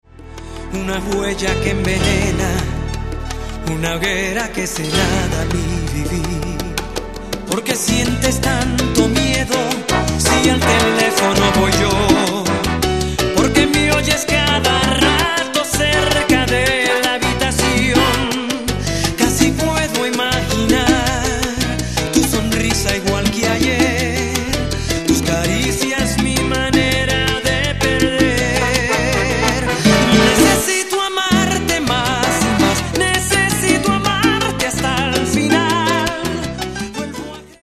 Salsa romantica